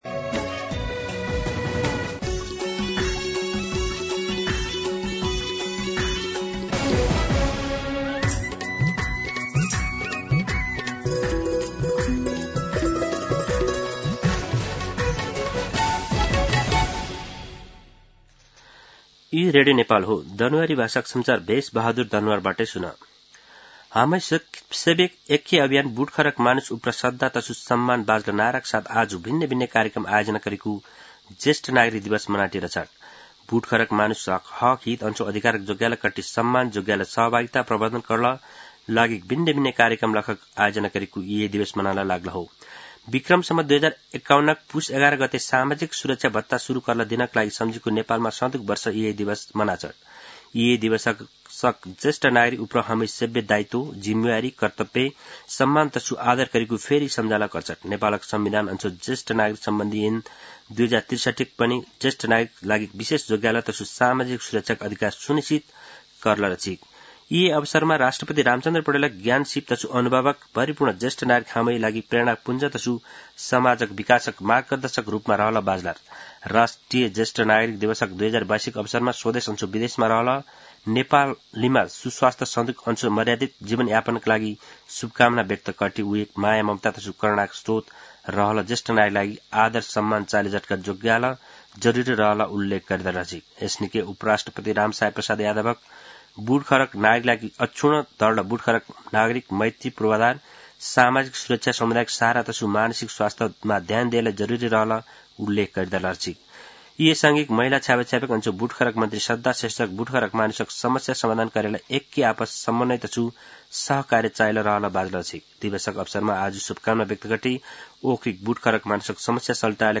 दनुवार भाषामा समाचार : ११ पुष , २०८२
Danuwar-News-9-11.mp3